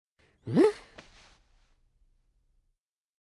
avatar_emotion_shrug.ogg